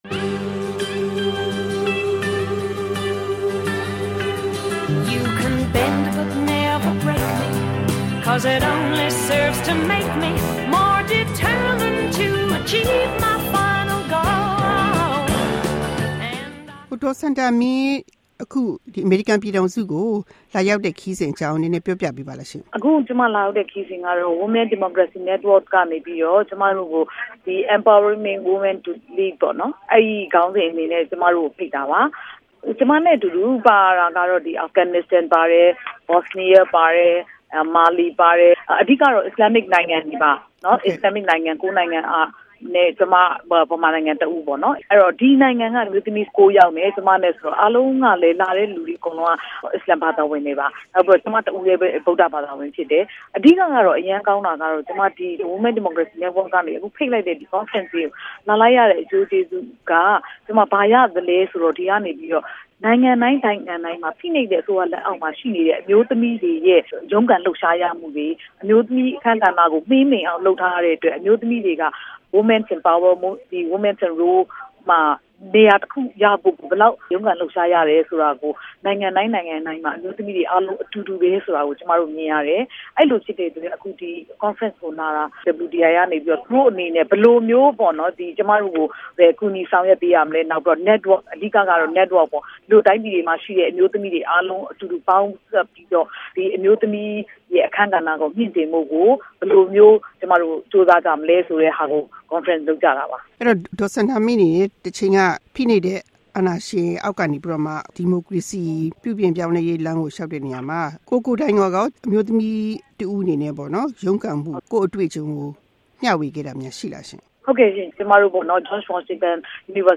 ဒေါ်စန္ဒာမင်းနဲ့မေးမြန်းချက်